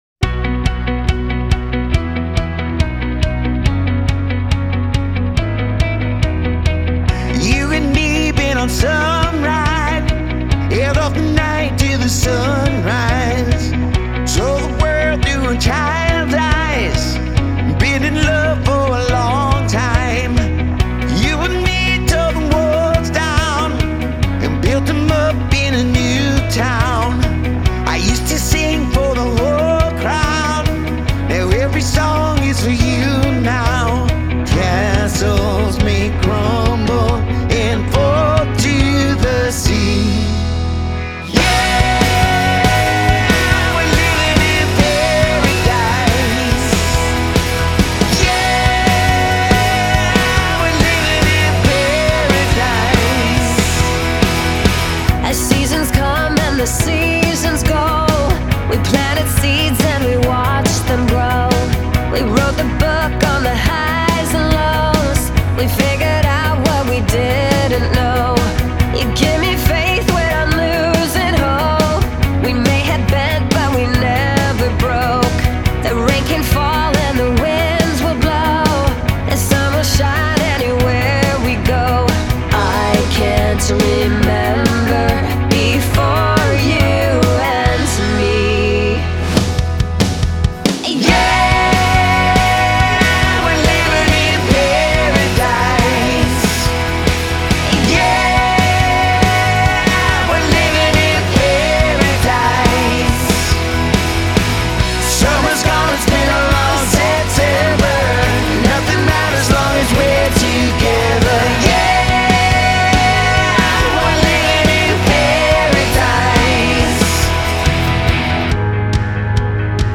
The result feels optimistic without being glossy.